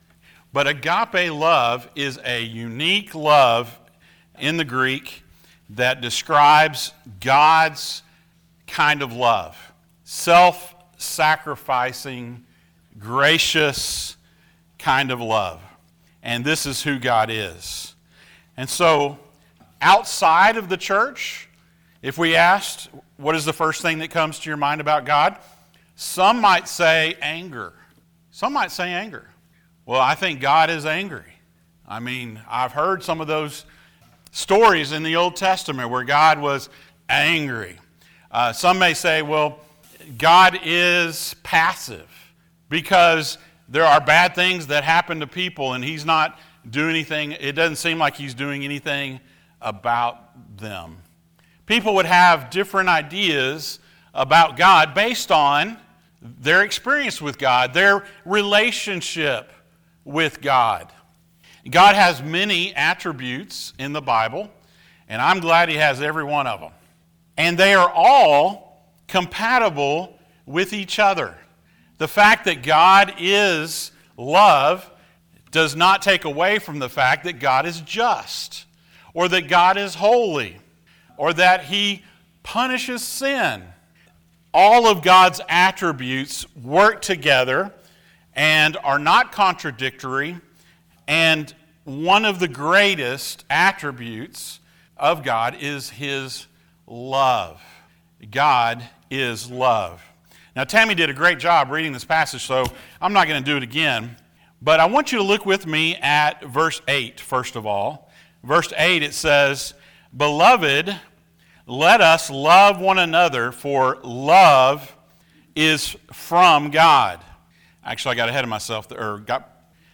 Passage: 1 John 4:7-12 Service Type: Sunday Morning If you live in our area